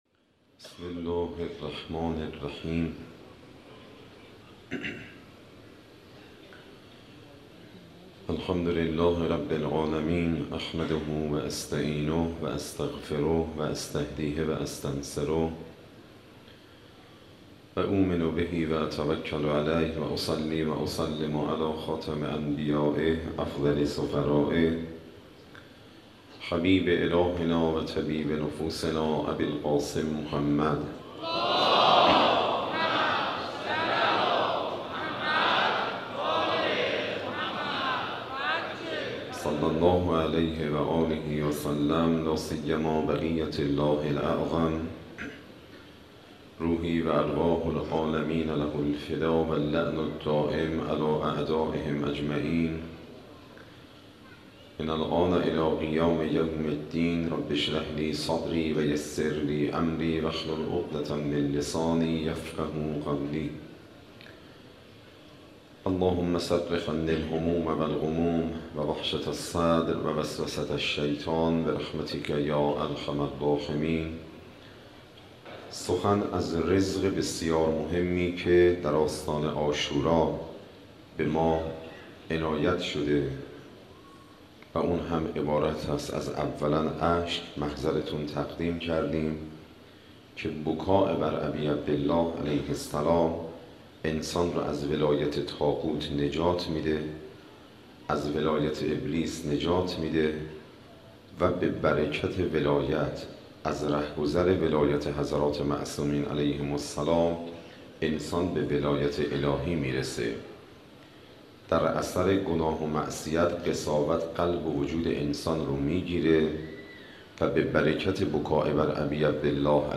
مناسبت : شب دوم محرم
Moharrame 93, Shabe 10, Sokhanrani.mp3